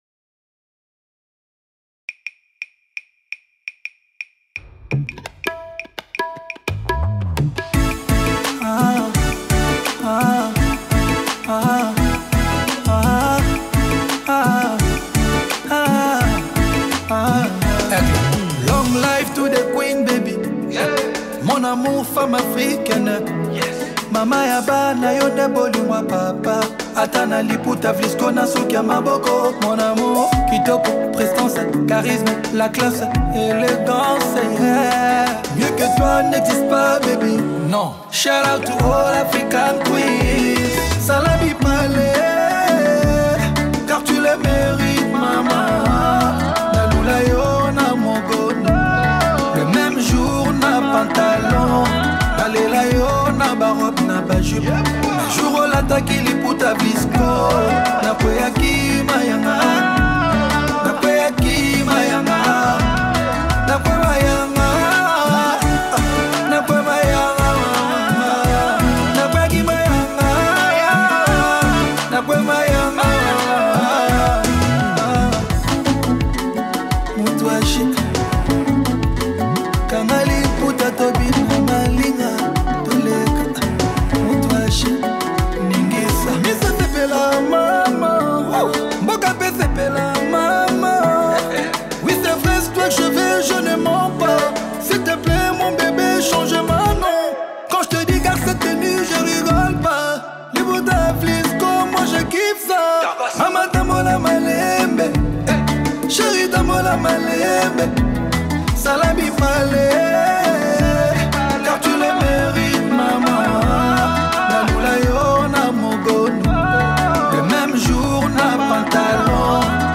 | Rumba